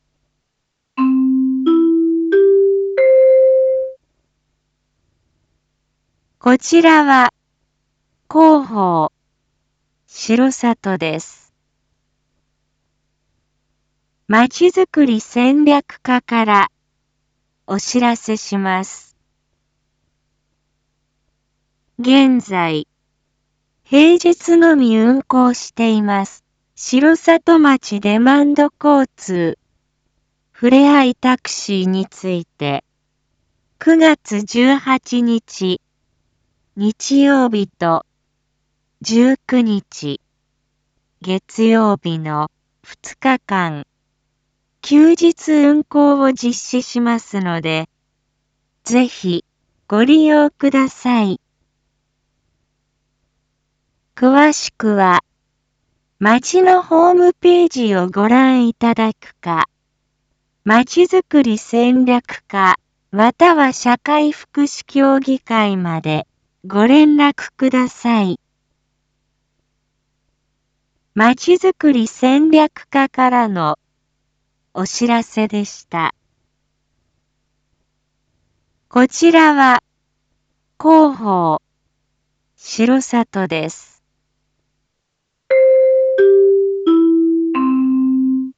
Back Home 一般放送情報 音声放送 再生 一般放送情報 登録日時：2022-09-15 19:01:29 タイトル：R4.9.15 19時放送分 インフォメーション：こちらは広報しろさとです。